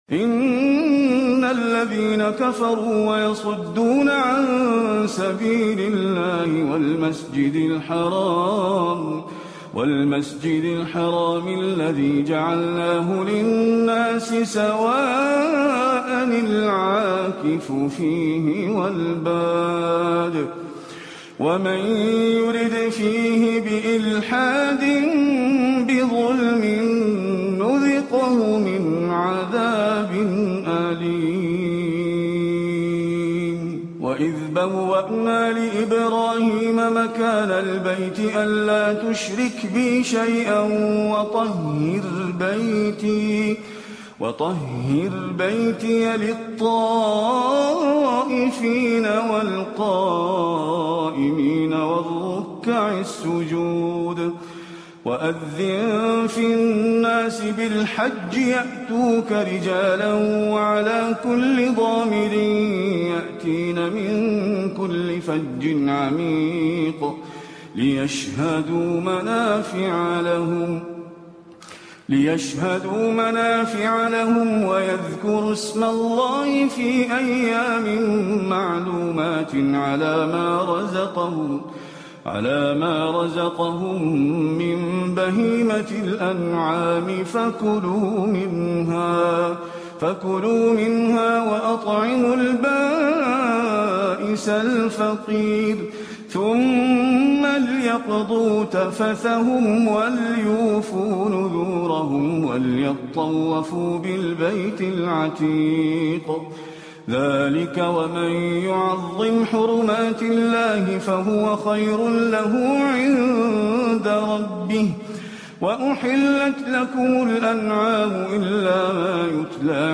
آيات الحج بأداء حجازي رائع من المسجد النبوي الشريف